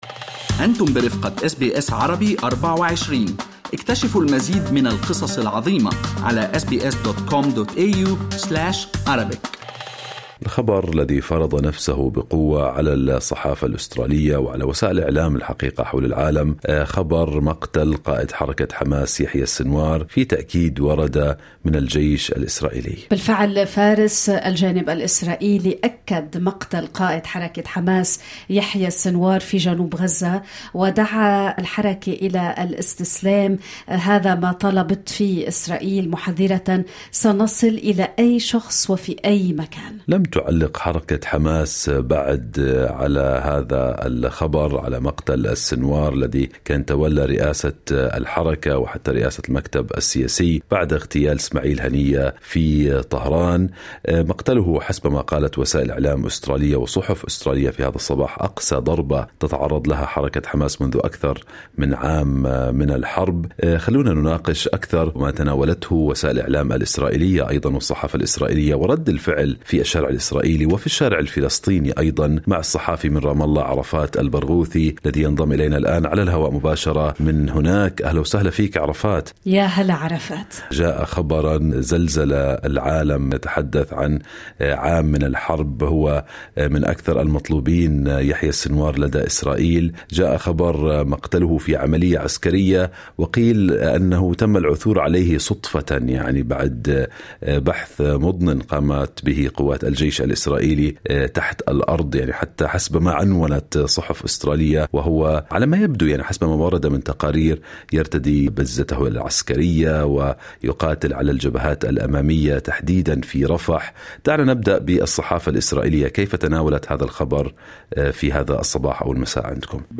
قُتل قائد حماس يحيى سنوار، على يد القوات الإسرائيلية، مما أنهى مطاردة استمرت عامًا لرجل يُعتبر العقل المدبر للهجوم الذي وقع في 7 أكتوبر، والذي أشعل الحرب في غزة. تحدثنا مع الصحافي الفلسطيني في رام الله